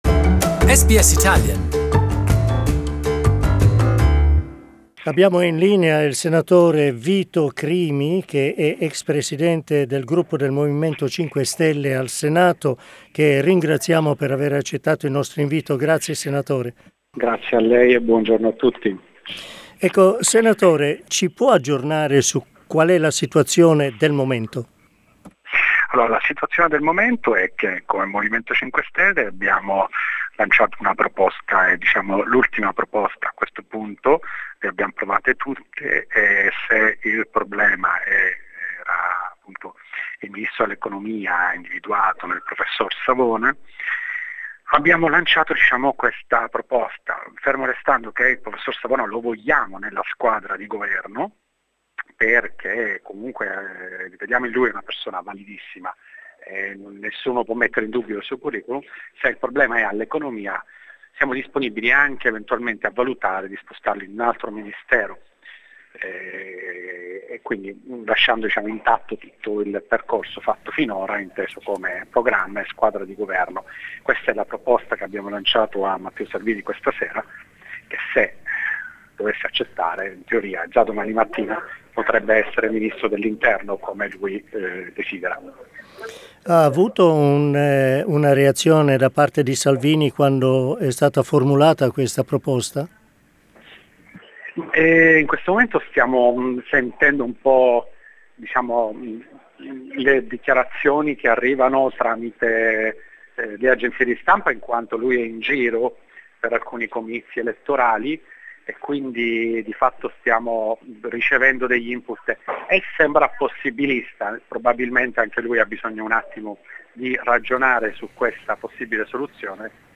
Intervista al Senatore Vito Crimi del Movimento Cinque Stelle.